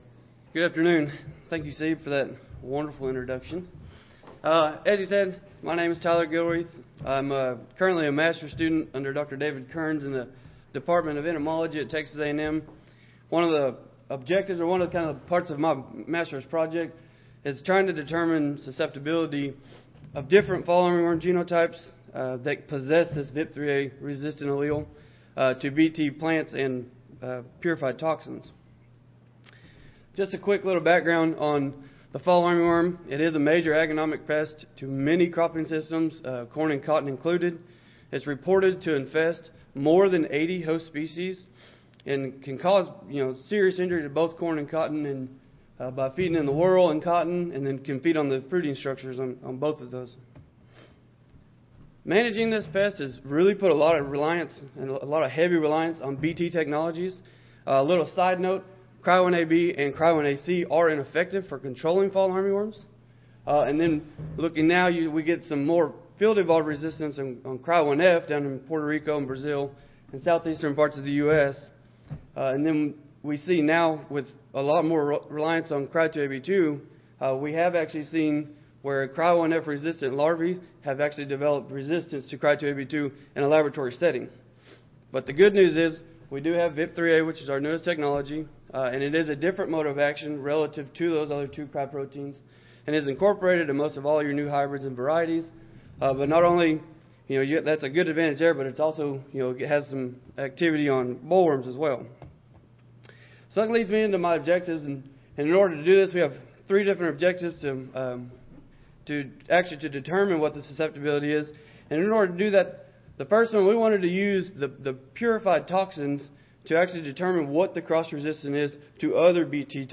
Recorded Presentation In this study, we are looking at the susceptibility of fall armyworm genotypes that are carrying Vip3A resistant alleles, to whole plant Bt corn and Bt cotton squares. We will also confirm resistance with purified Bt proteins.